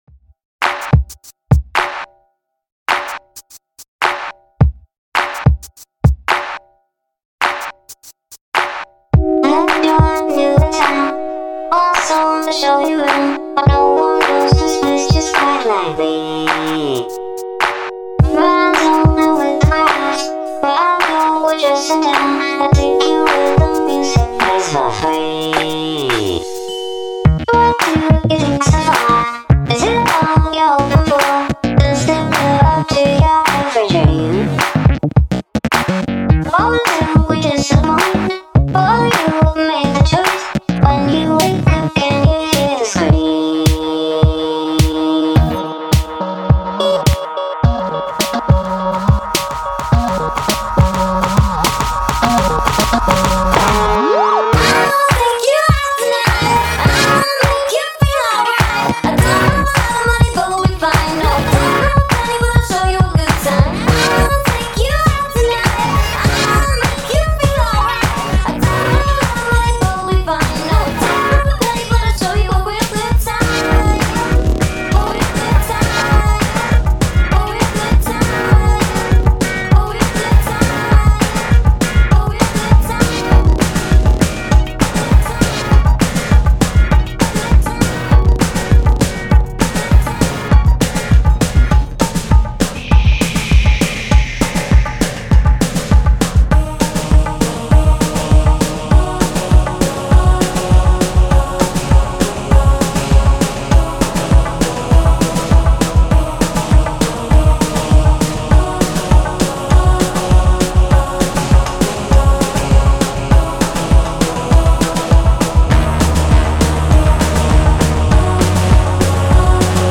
house, roba fidget, remix